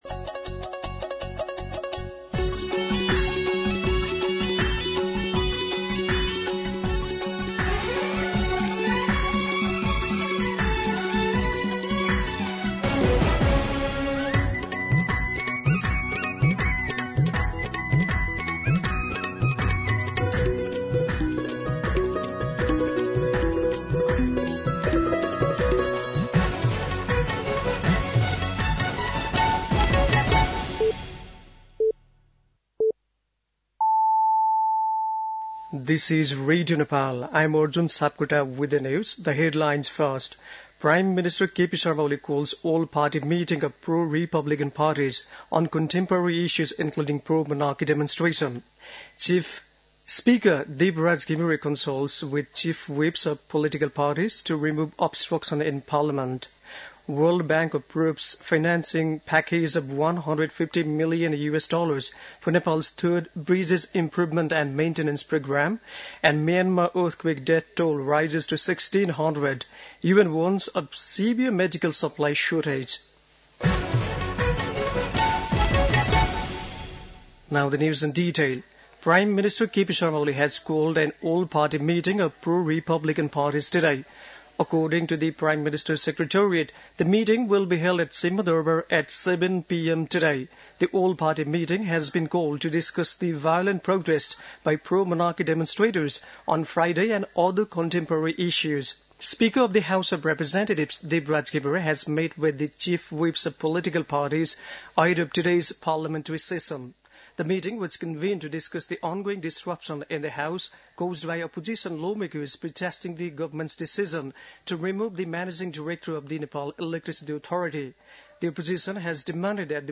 दिउँसो २ बजेको अङ्ग्रेजी समाचार : १७ चैत , २०८१
2pm-English-News-12-17.mp3